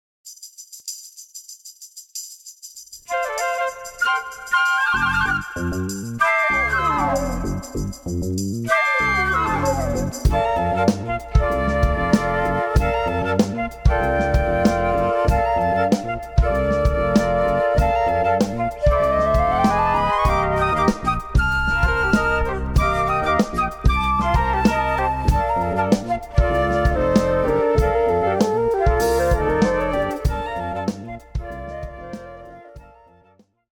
More jazz titles
fretless bass